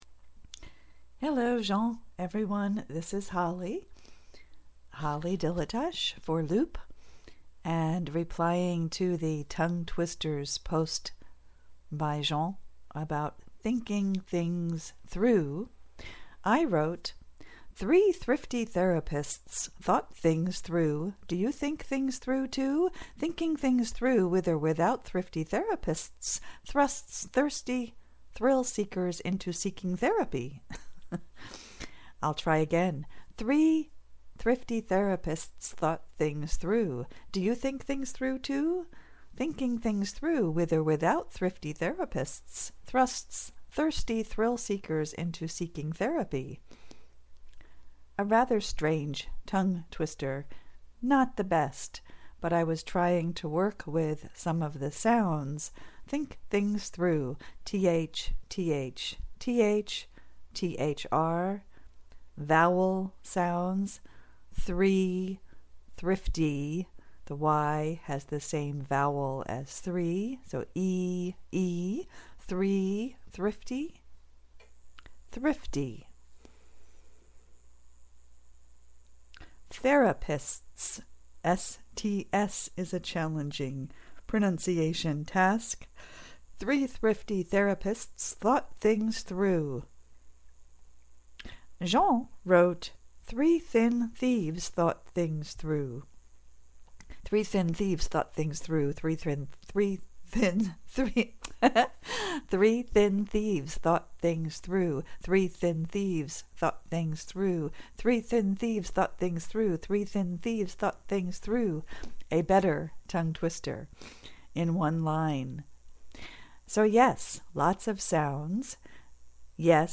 [pronunciation] think things through (tongue twister)